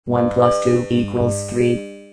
Roboterstimmen Mary, Mike...
Microsoft Speech Synthesis Engine